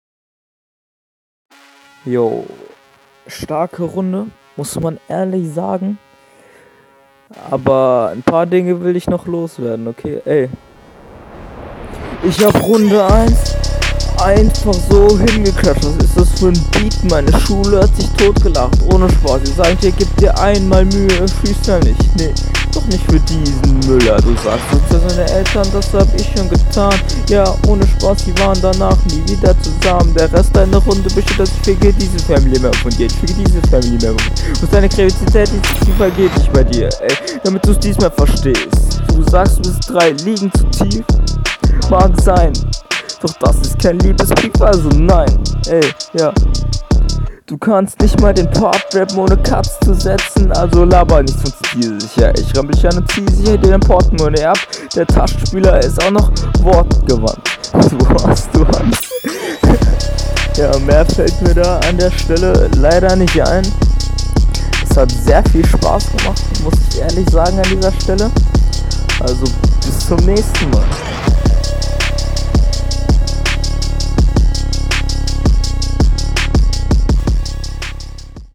Hört sich etwas an als Hättest das Mikro im Mund beim Aufnehmen. versuch mal ein …